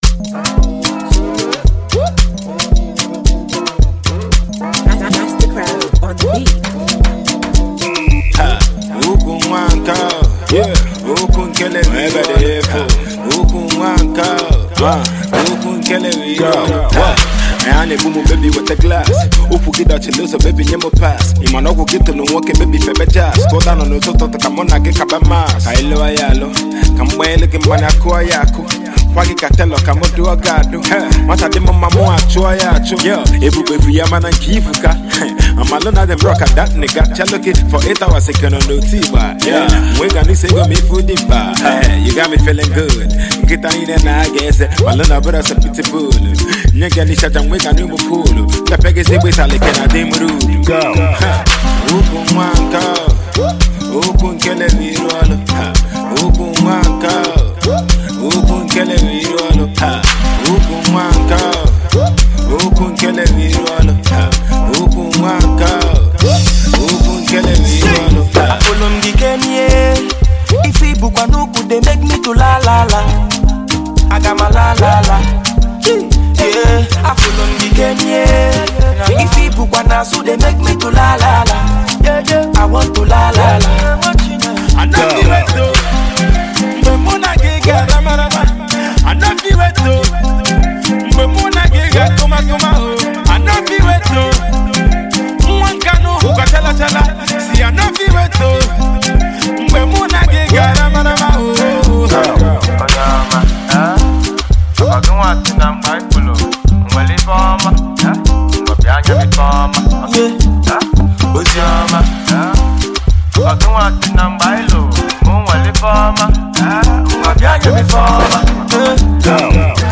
The hook is catchy and the flow is also A1.